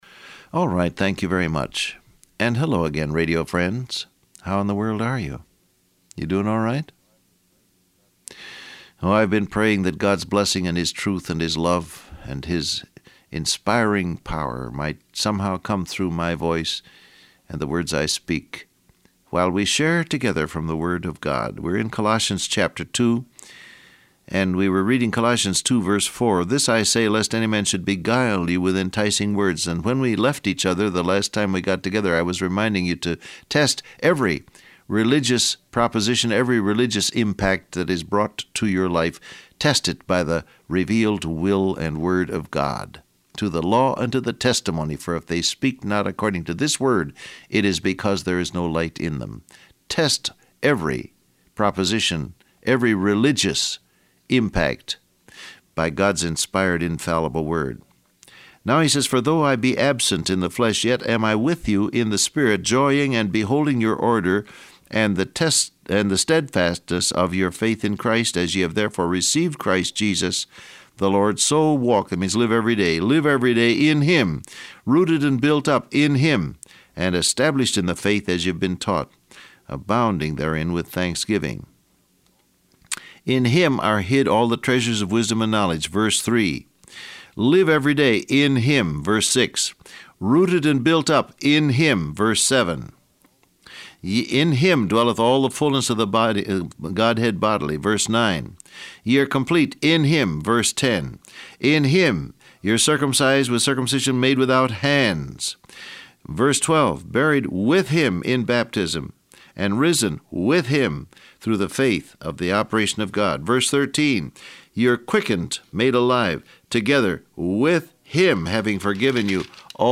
Download Audio Print Broadcast #1885 Scripture: Colossians 2:4-13 Transcript Facebook Twitter WhatsApp Alright, thank you very much.